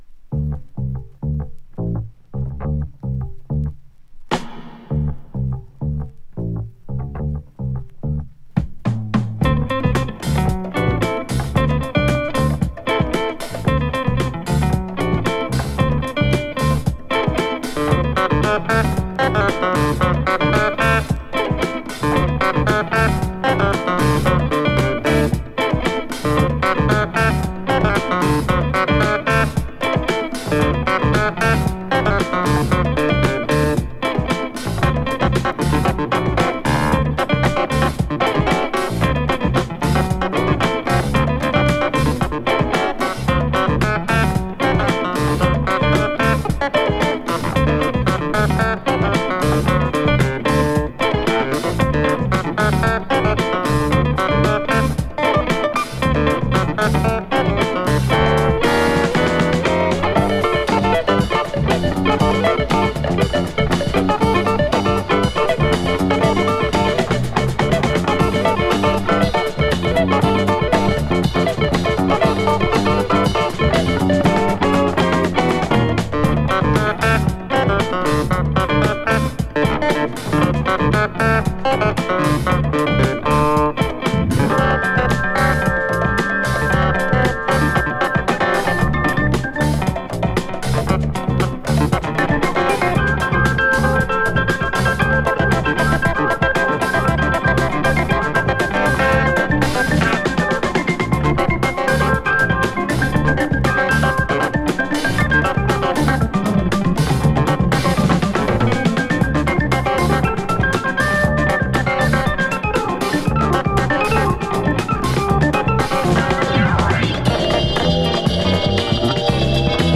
> JAZZ FUNK/RARE GROOVE
ブギーなベースラインで攻めるジャズ・ファンク